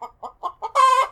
sounds_chicken_01.ogg